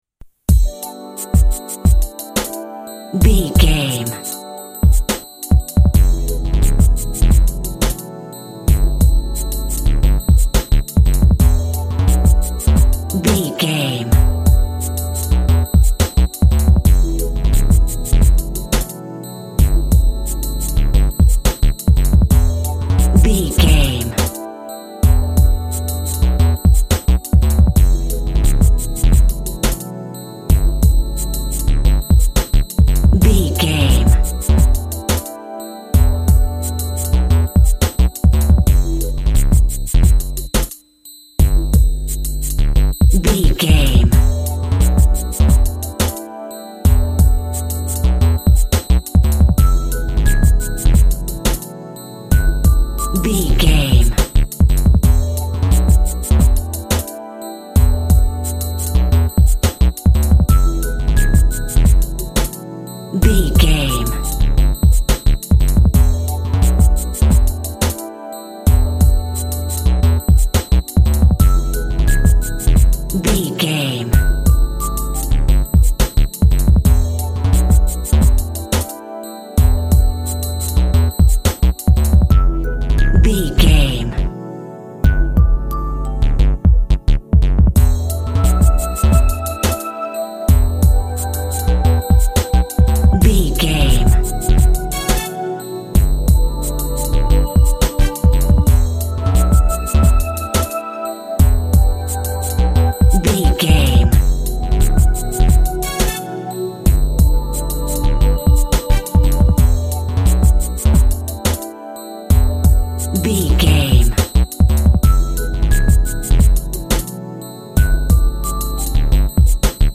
Aeolian/Minor
synth lead
synth bass